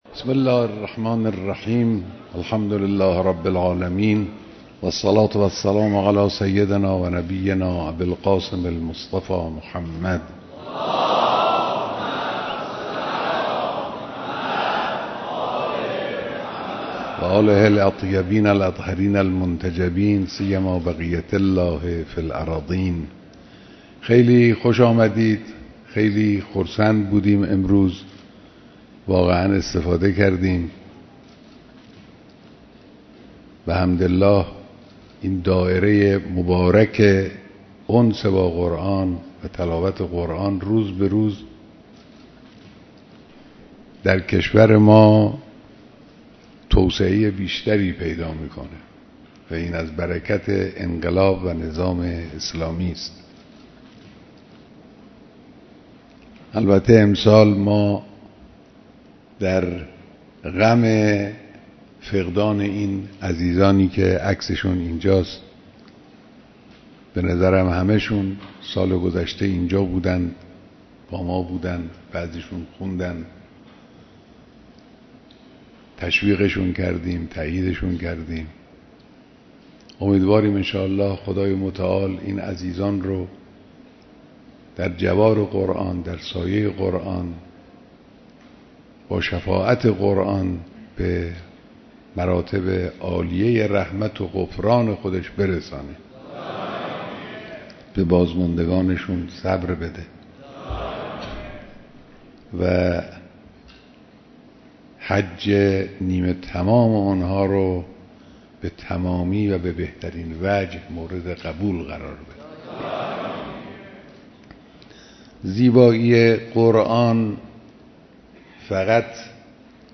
بیانات در محفل انس با قرآن کریم